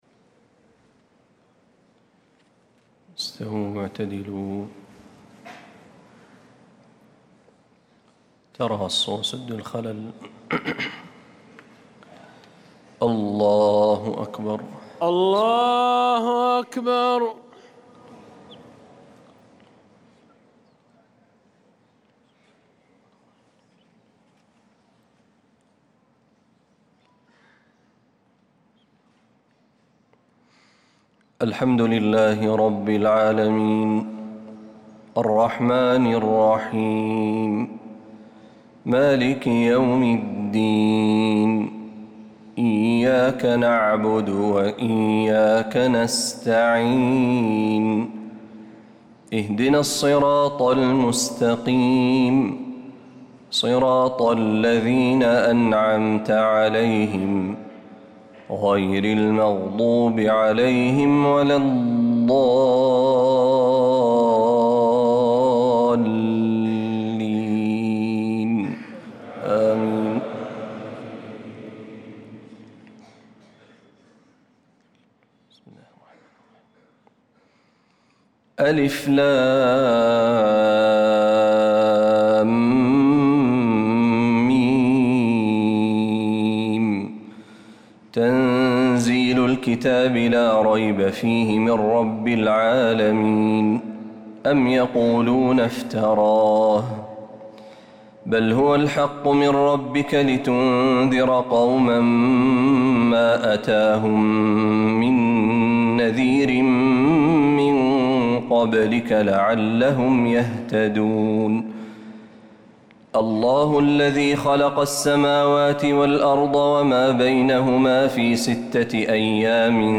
صلاة الفجر للقارئ محمد برهجي 29 ربيع الآخر 1446 هـ
تِلَاوَات الْحَرَمَيْن .